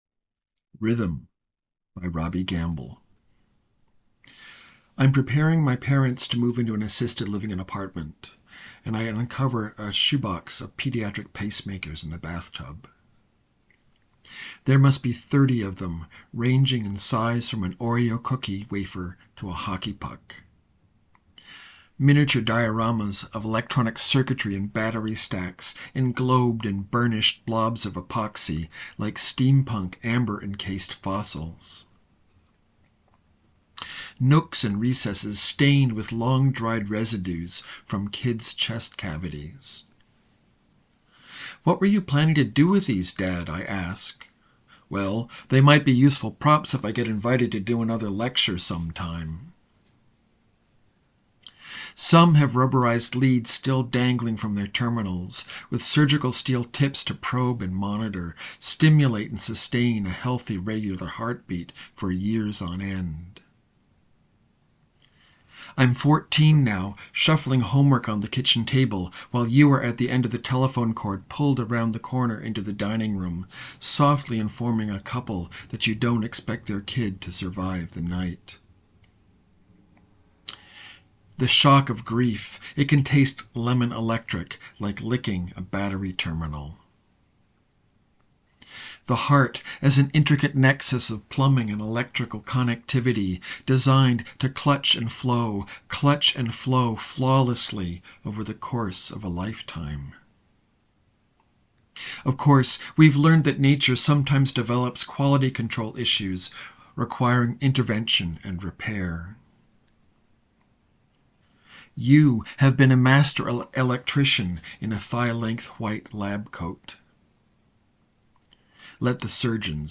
Press Play to hear the author read their piece.